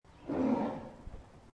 Tiger Téléchargement d'Effet Sonore
Tiger Bouton sonore